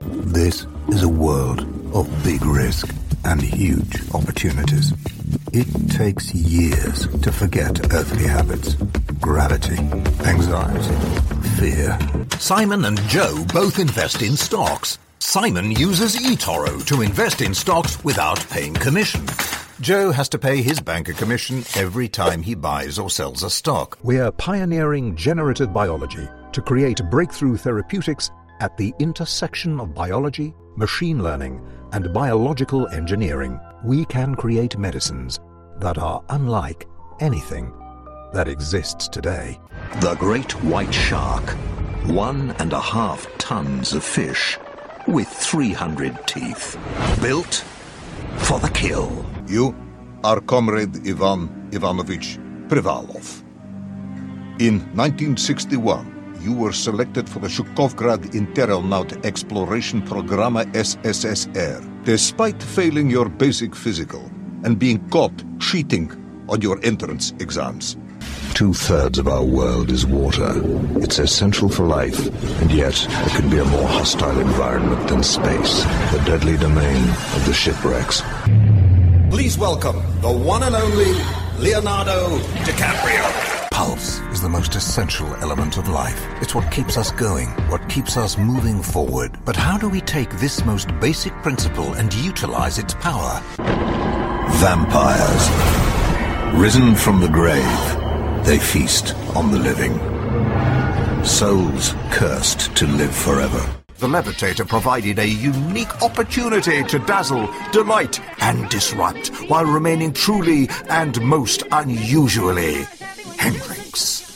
Demo
Adult
Has Own Studio
german | natural